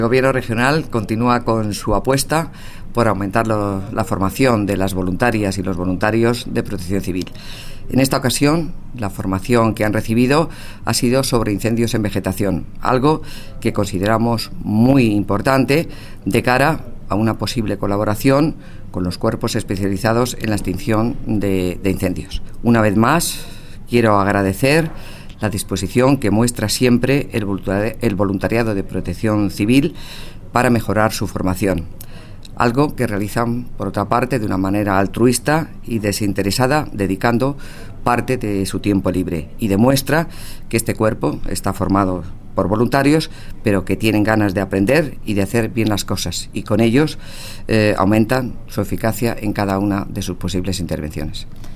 La directora provincial de Hacienda y Administraciones Públicas en Guadalajara, Ángela Ambite, habla del curso de incendios en vegetación en el que han participado voluntarios de Protección Civil de la provincia de Guadalajara.